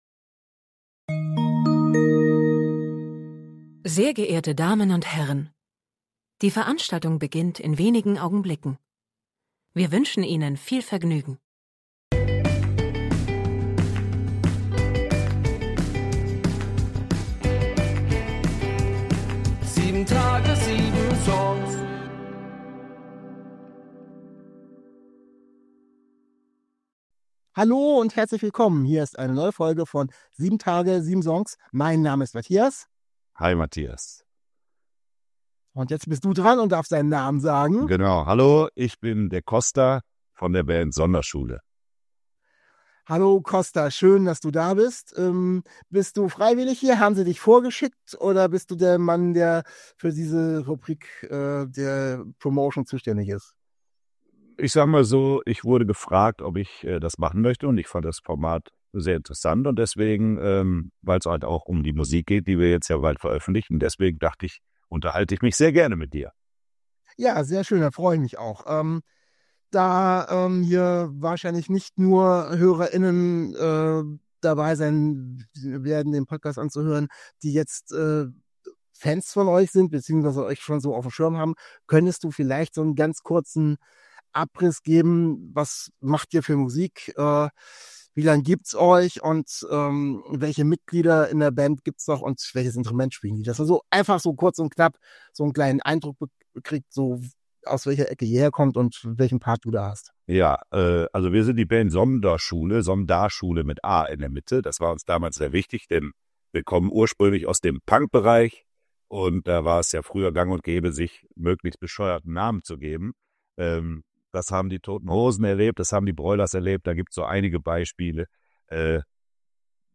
In einem sehr informativen und unterhaltsamen Gepräch erzählt er von den Songs des neuen Albums und gibt dabei auch ein paar Anekdoten preis.